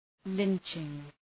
Shkrimi fonetik {‘lıntʃıŋ}